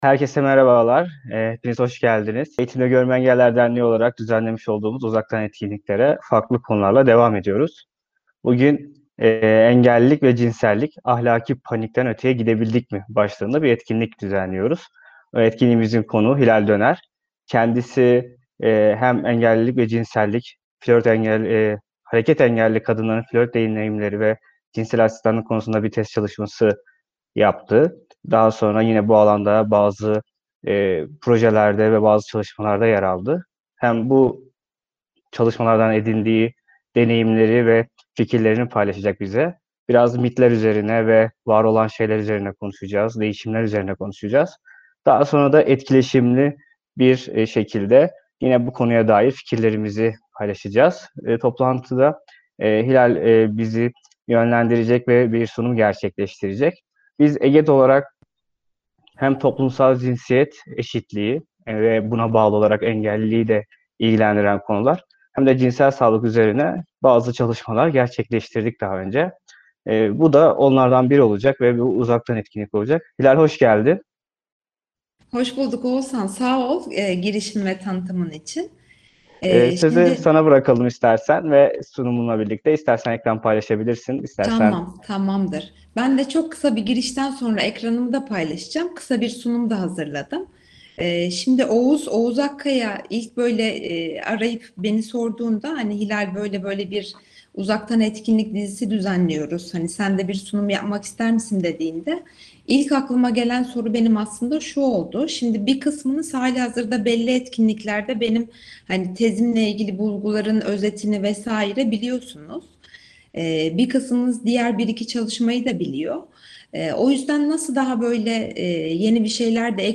Engellilik ve Cinsellik: “Ahlaki Panik”ten Öteye Gidebildik Mi? başlıklı Uzaktan Etkinlik, 20 Kasım 2020 tarihinde, Google Meet platformunda düzenlendi.